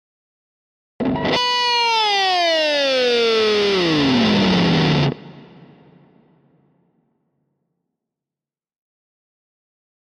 Rock Guitar Distorted FX 1 - Going Down Reef - Lower